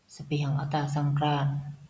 Аудиофайл с произношением.